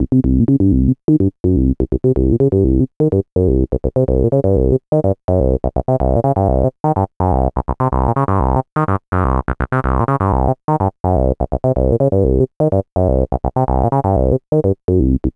cch_acid_loop_basement_125.wav